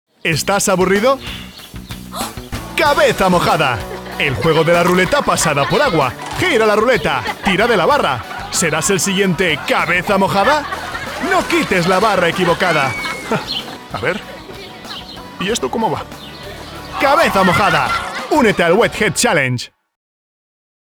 Estudio de grabación con acústica controlada y equipos profesionales como el micrófono Shure SM7B y la interfaz M-Audio.
Locutor español, castellano, gallego, voz joven, adulto, fresca, profesional, amigable, natural, corporativa, que no suene a locutor.
Sprechprobe: Werbung (Muttersprache):
Studio recording with controlled acoustics and professional equipment like Shure SM7B microphone and M-Audio interface .